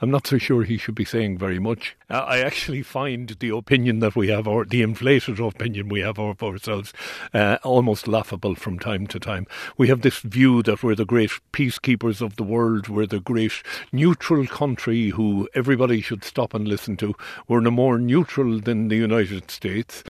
Former member of the Defence Forces and Independent Senator Gerard Craughwell says the Taoiseach should adopt a listening approach today: